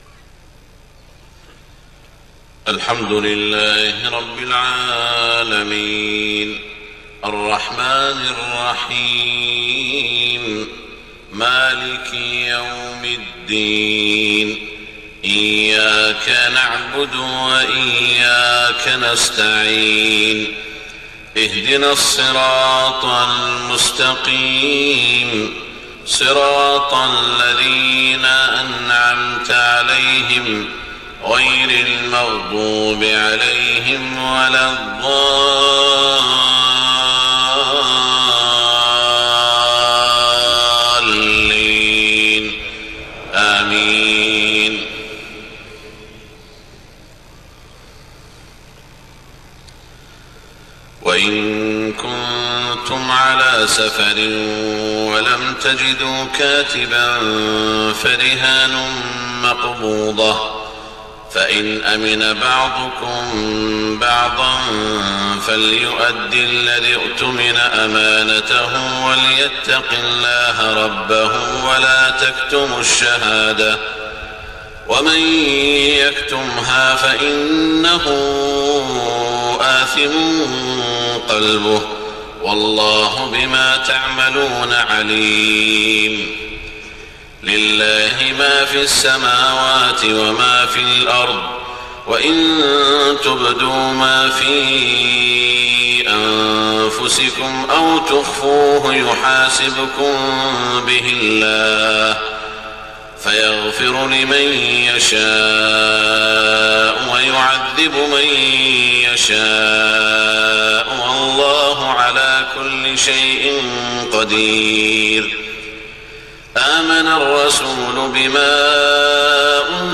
صلاة الفجر 23 محرم 1429هـ من سورتي البقرة و آل عمران > 1429 🕋 > الفروض - تلاوات الحرمين